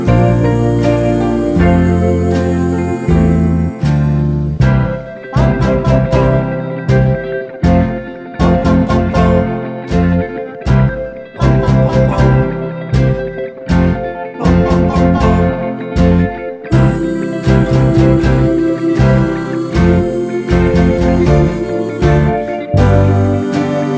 4BPM Faster Easy Listening 2:18 Buy £1.50